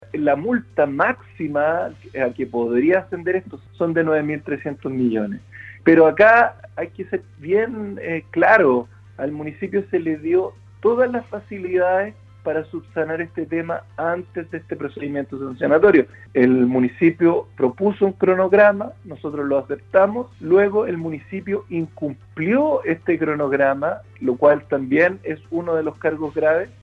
En conversación con Radio el Superintendente de Medio Ambiente, Cristóbal De La Maza, dio cuenta de la formulación de tres cargos en contra del municipio de Ancud por su gestión del relleno sanitario Puntra-El Roble, proyecto del cual es titular.